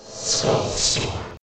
skull_storm.ogg